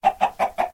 Minecraft / mob / chicken / say2.ogg